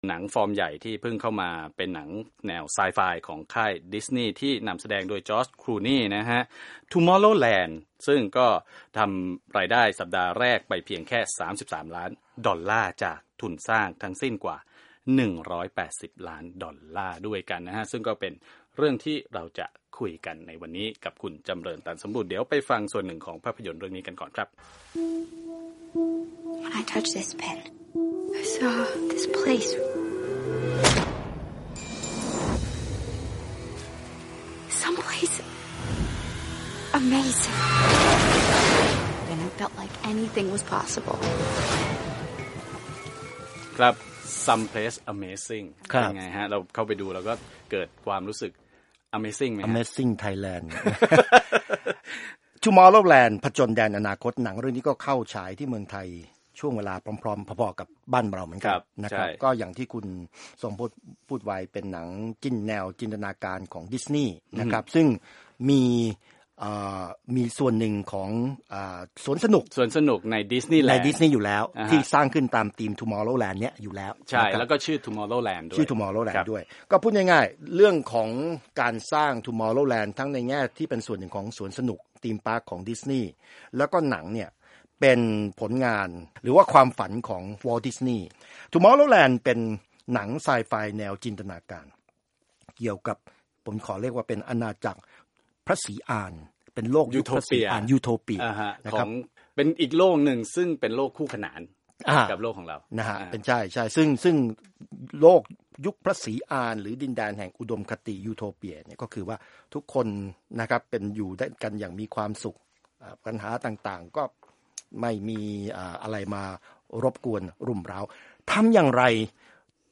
คุยหนังใหญ่จากค่ายดิสนีย์ Tomorrowland